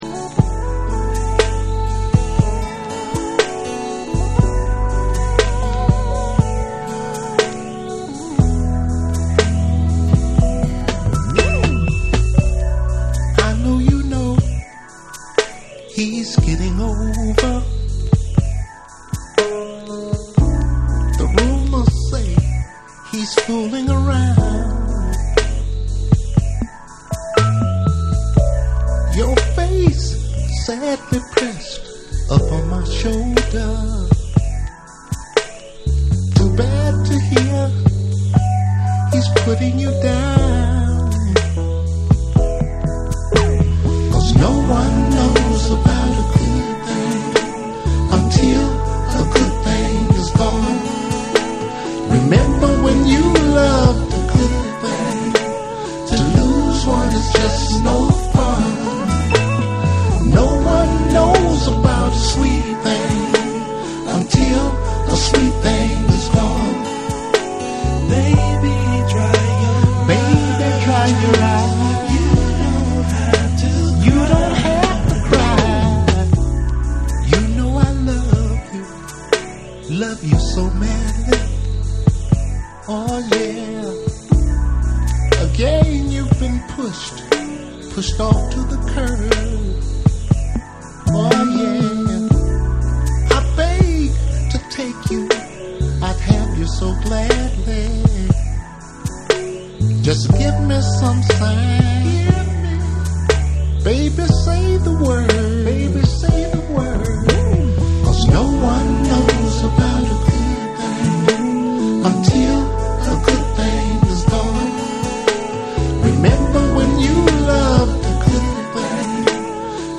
アーバン・メロウなトラックにハートウォーム／ソウルフルな歌声が染み渡る珠玉の1曲。
SOUL & FUNK & JAZZ & etc / ALL 500YEN